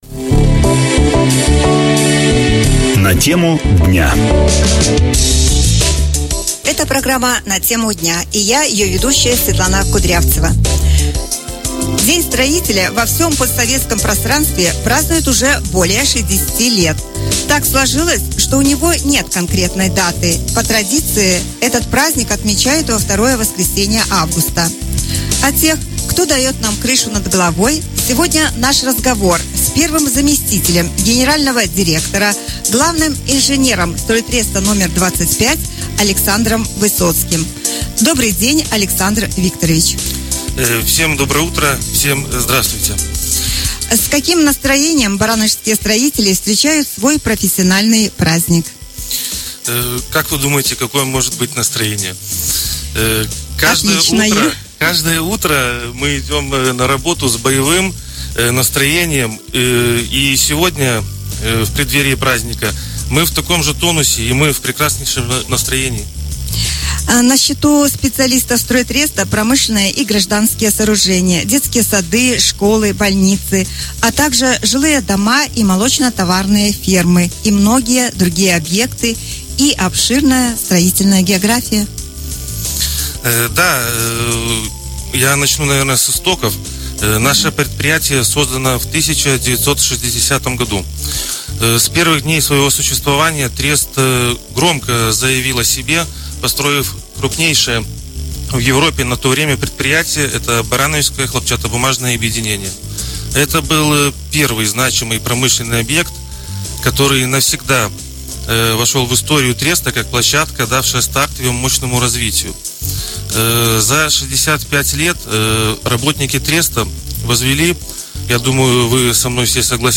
разговор с первым заместителем генерального директора